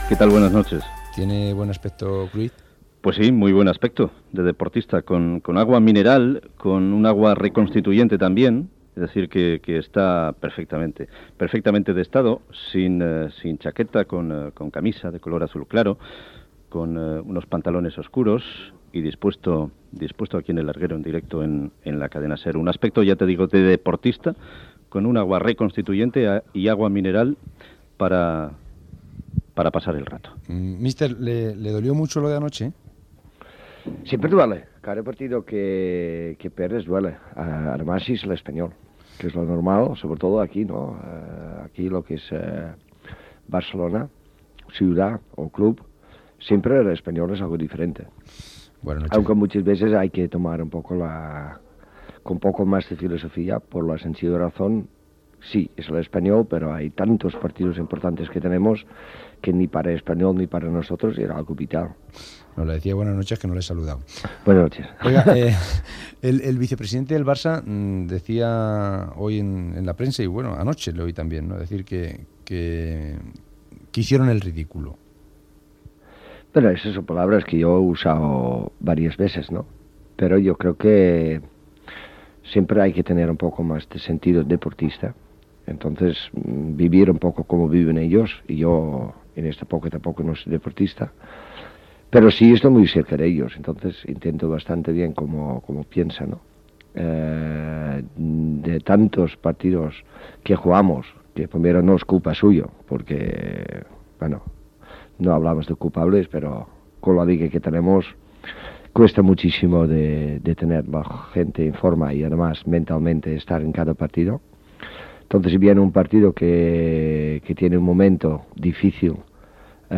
01390f8944f077eb5b9e47a436a25110a8cb6aa9.mp3 Títol Cadena SER Emissora Ràdio Barcelona Cadena SER Titularitat Privada estatal Nom programa El larguero Descripció Entrevista a l'entrenador del Futbol Club Barcelona Johan Cruyff. S'hi parla del partit que ahir va perdre el Barça per 1 a 5 davant del R.C.D.Espanyol a la Copa Catalunya, del seu fill, de la present temporada i de la propera